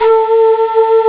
It sounds like a problem with aliasing, the samplerate of the wav is 8485 Hz and depending on the player, it can sound awful if not filtered.
I uploaded a new version of the sample with correct loop information : try to replace Pan.wav by Pan2.wav and tell me if this is ok!
It was really nice of you to try that, but I loaded the new sample and it doesn't change anything, there is still that metallic sound.